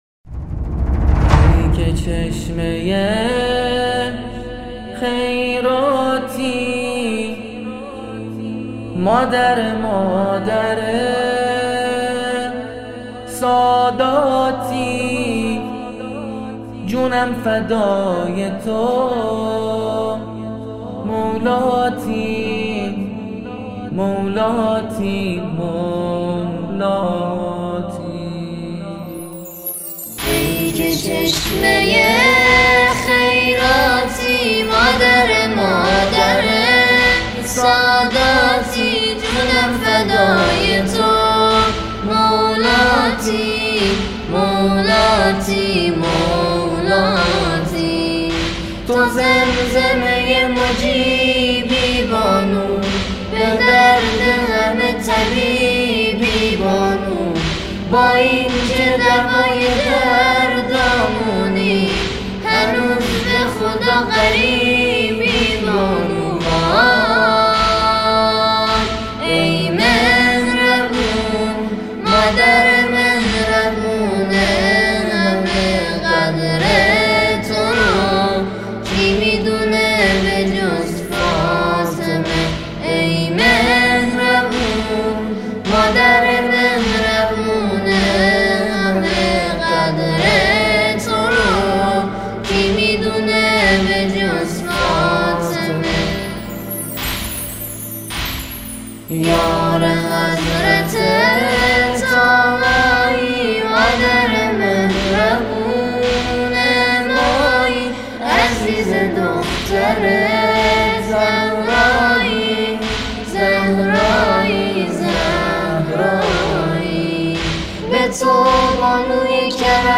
سرودهای حضرت خدیجه